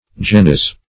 Genys \Ge"nys\ (j[=e]"n[i^]s), n.
genys.mp3